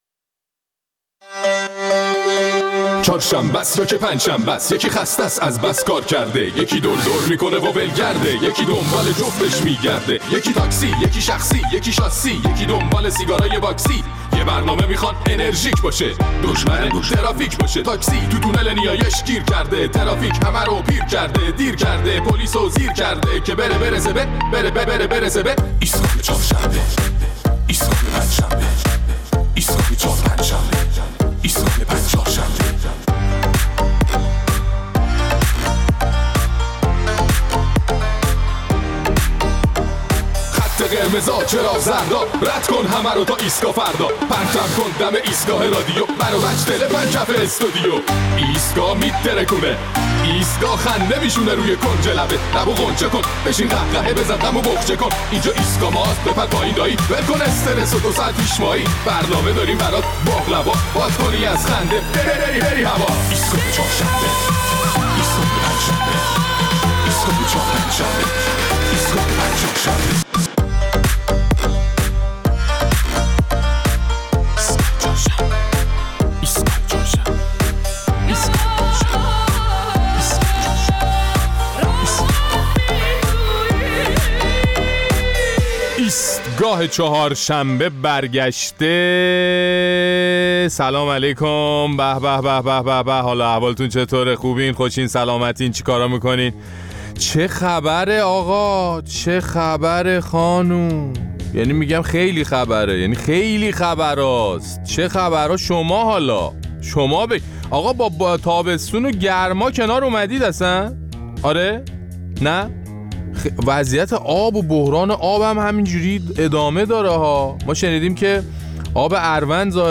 در این برنامه نظرات و مشاهدات شنوندگان را در مورد اعتصابات و اعتراضات هفته اخیر کسبه در بازارهای تهران و شهرهای مختلف کشور می‌شنویم.